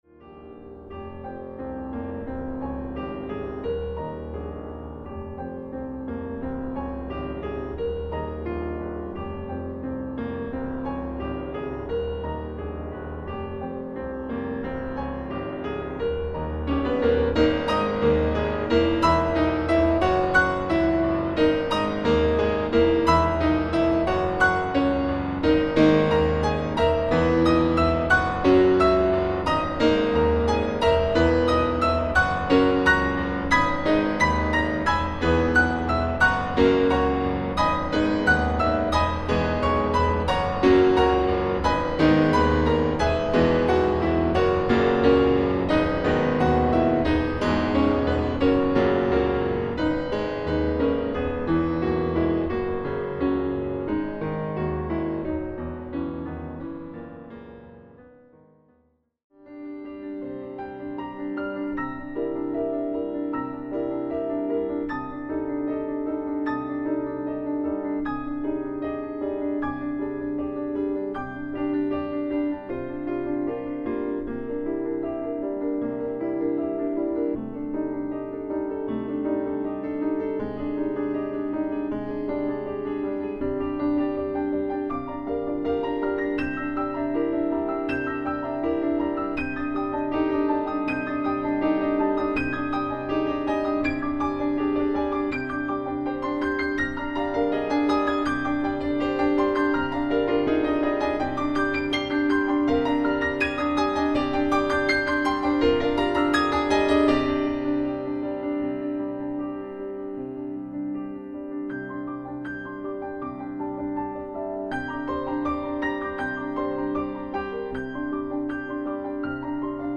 Audio preview of all four movements: